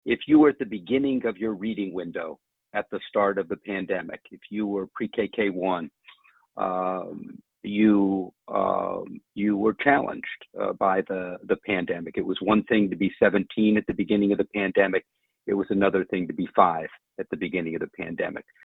AUDIO: State Superintendent Dr. Rice says M-STEP scores show many third graders falling short in reading